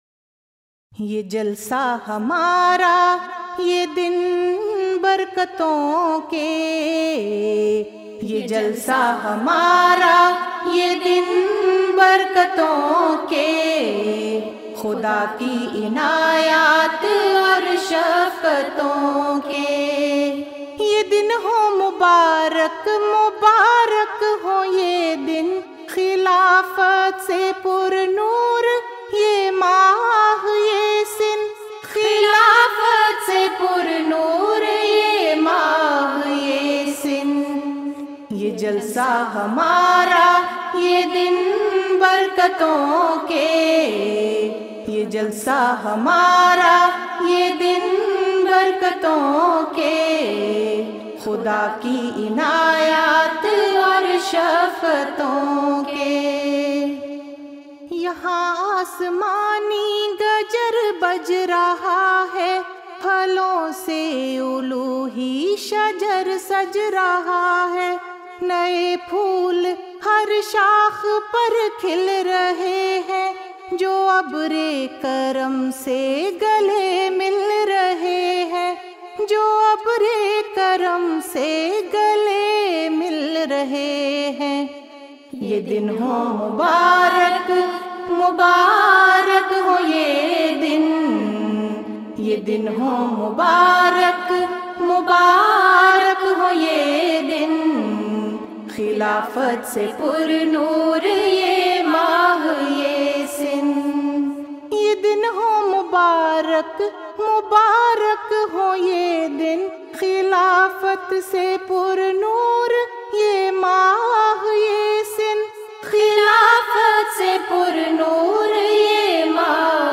Voice: Member Khuddamul Ahmadiyya
Jalsa Salana UK 2009